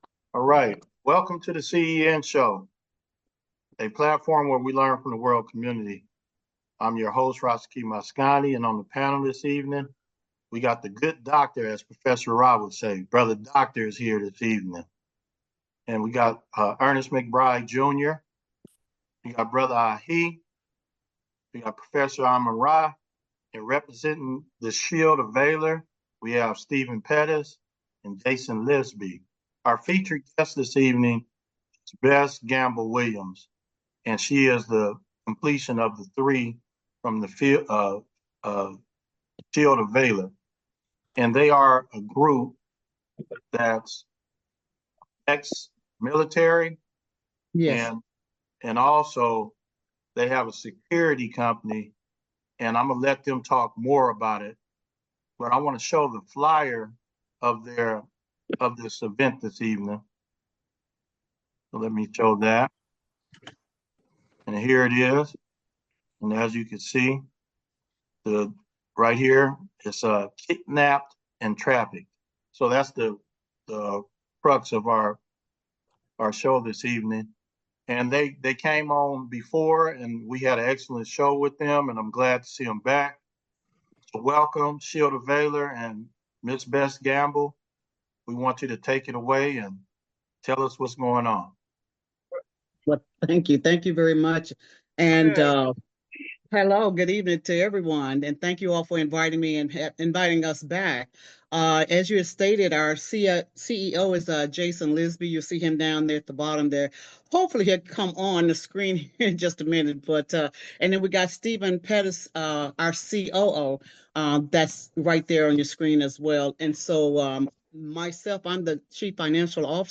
interviews Shield of Valor Federal Investigators.